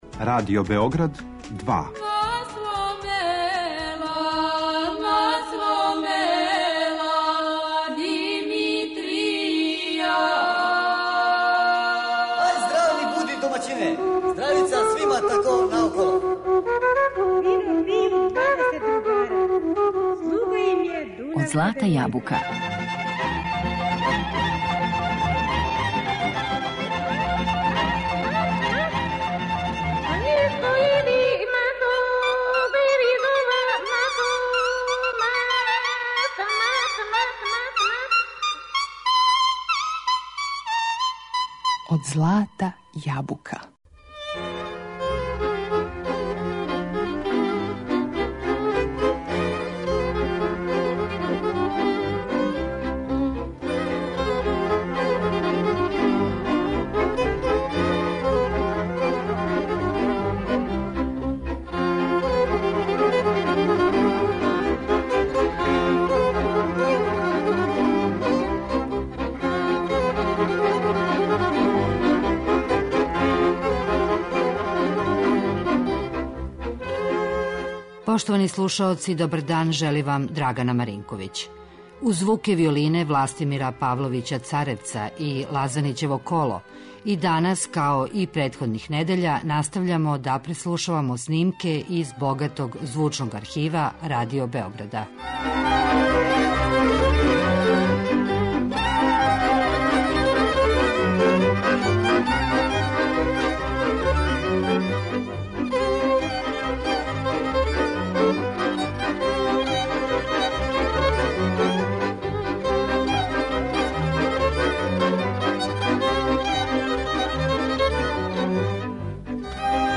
И данас, као и претходних недеља, настављамо да преслушавамо снимке из богатог звучног архива Радио Београда.